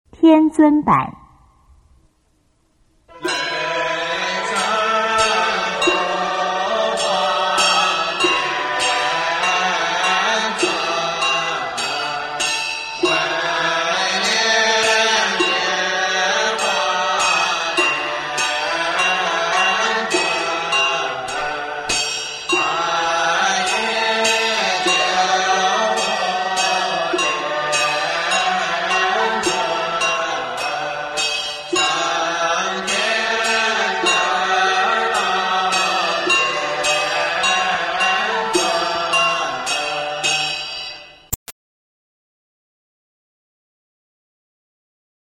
中国道教音乐 全真正韵 天尊板